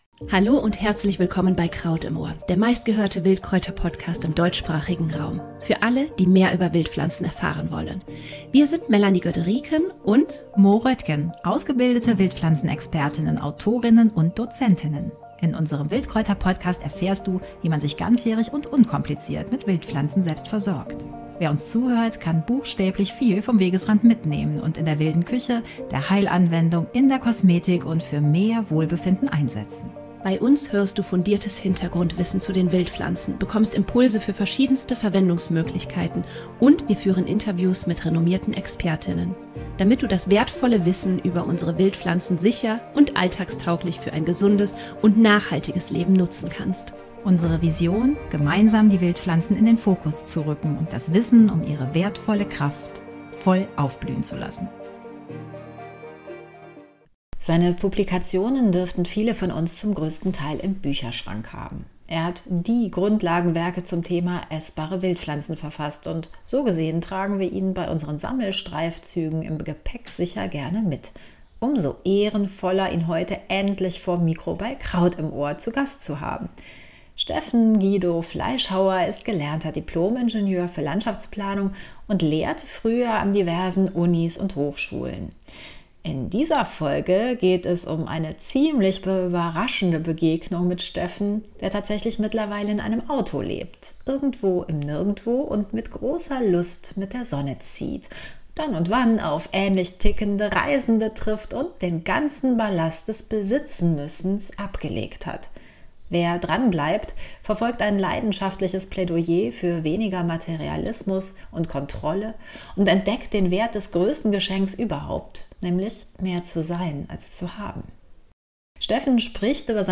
Kraut-Interview: Wildkräuter statt Hamsterrad - ein radikales Plädoyer für weniger ~ Kraut im Ohr - Dein Wildkräuter Podcast